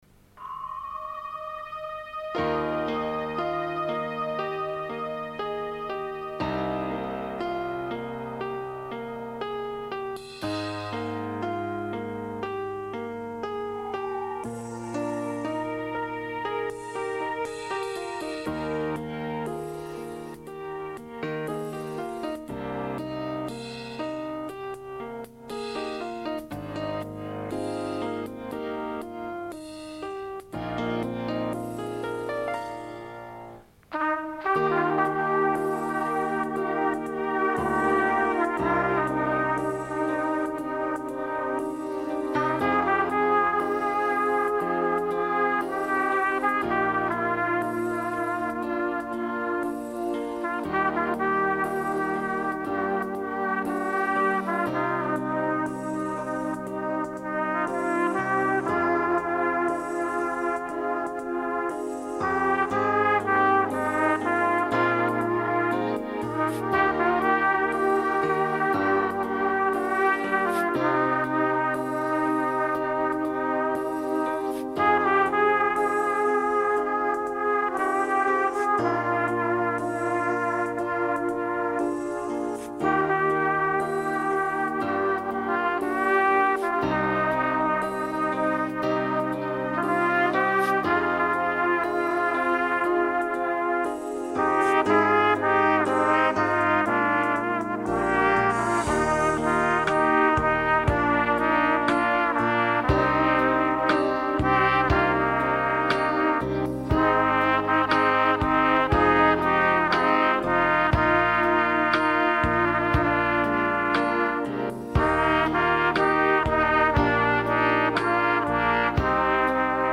trumpet 2
Kategorie: Instrumental